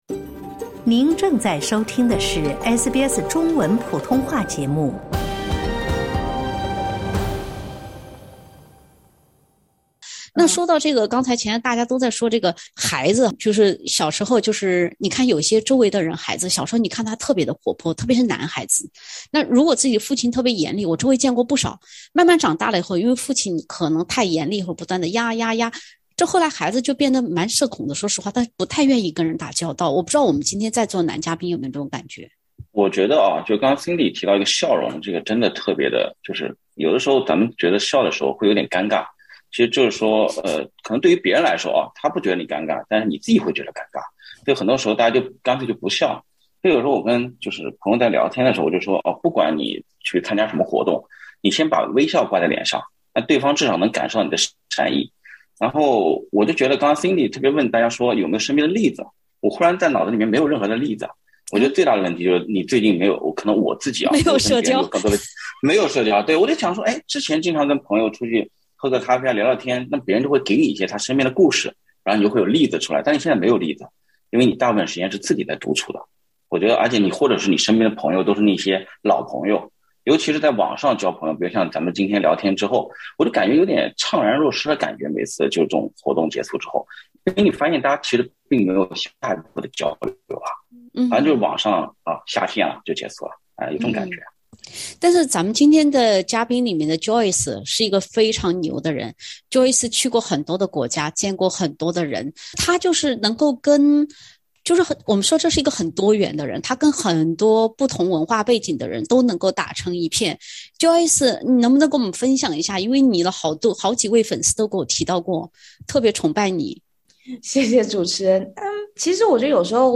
SBS全新谈话类节目《对话后浪》，倾听普通人的烦恼，了解普通人的欢乐，走进普通人的生活。
本期话题：来看看，你是“精芬”吗。（点击封面图片，收听风趣对话）